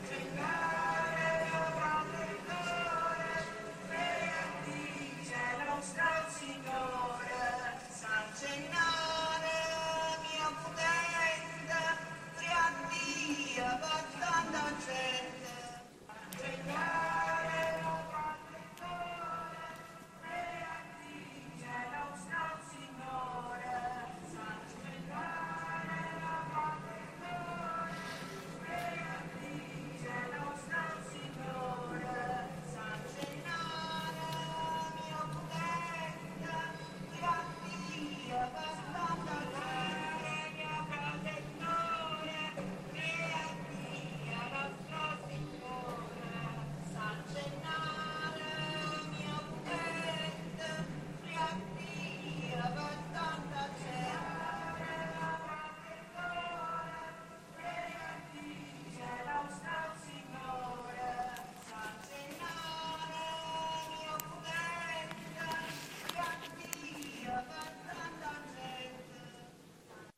Extrait sonore d’une vidéo encastrée dans l’une des vitrines du musée Maillol, où des Catholiques célébrant en chants et prières la liquéfaction du sang de San Gennaro (16 décembre 2012) baisent un à un ce qui ressemble vaguement à un ostensoir.